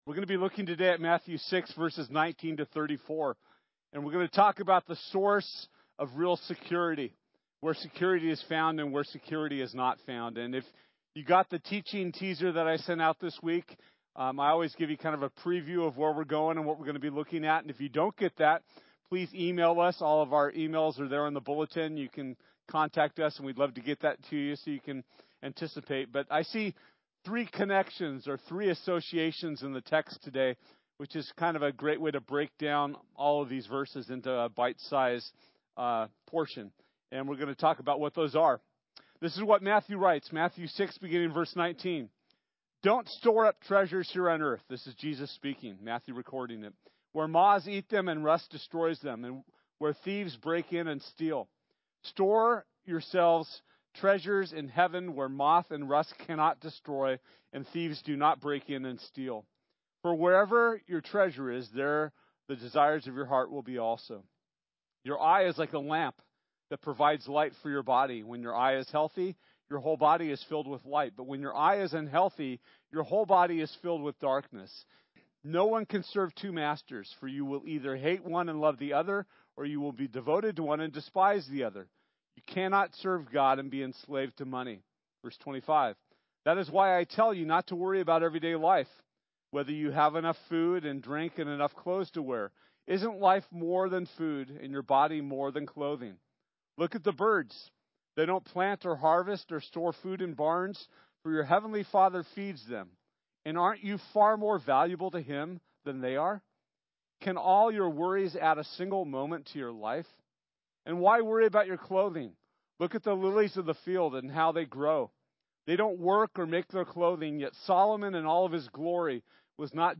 Matthew 6:19-34 Service Type: Sunday This week we’ll be looking at Matthew 6:19-34.